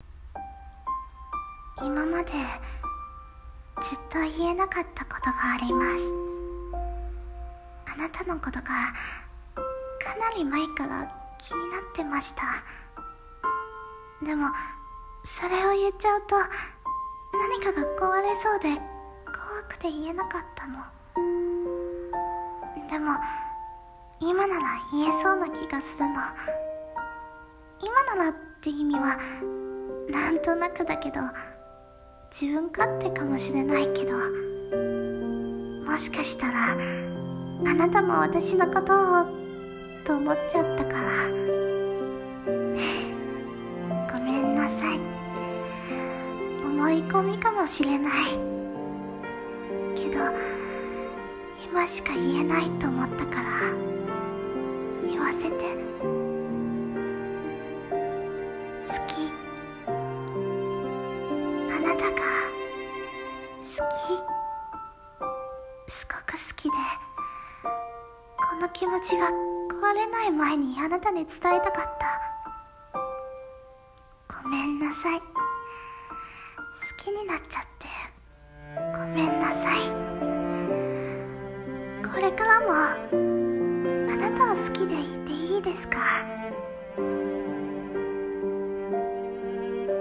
朗読台本:セリフ】秘めた想い